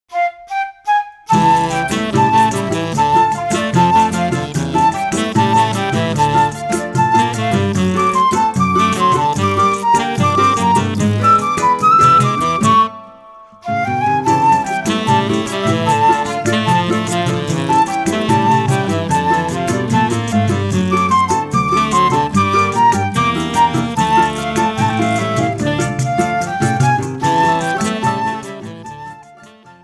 – áudio completo com regional, solo e contraponto.
flauta
saxofone tenor